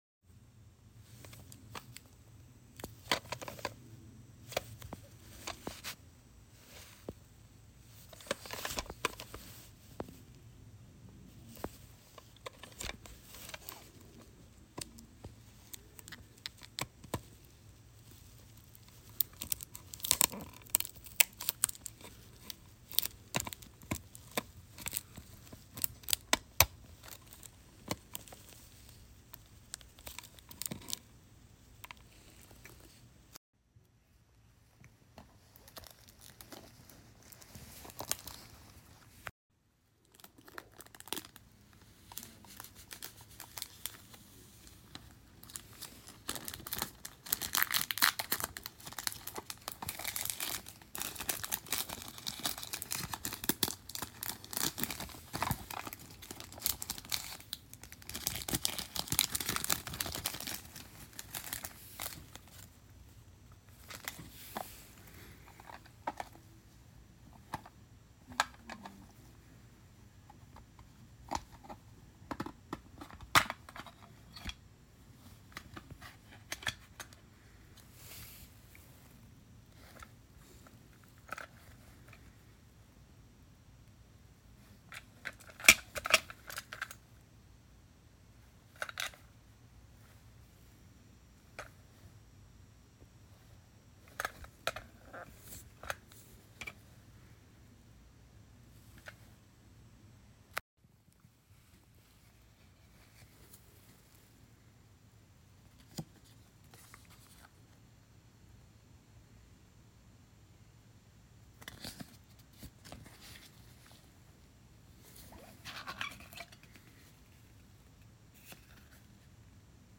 POV you’ve just bought a new cassette tape and your opening it up for the first time